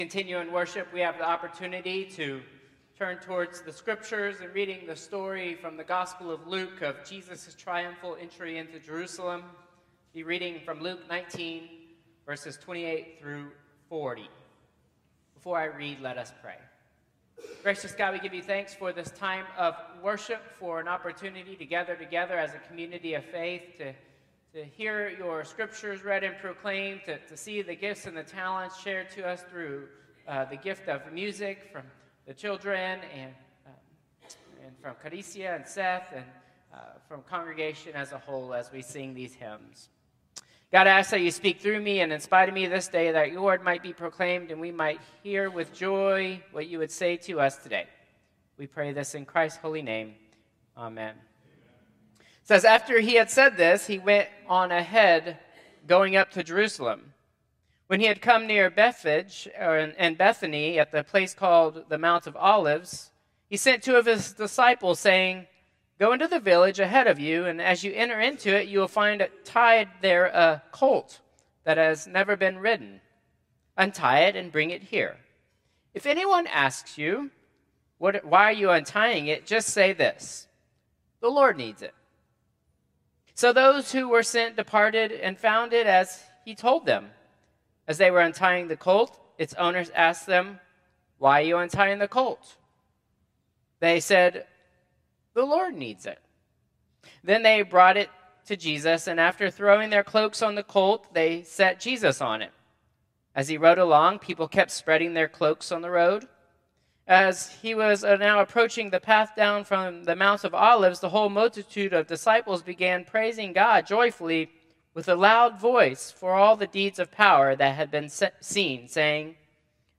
Traditional Service 4/13/2025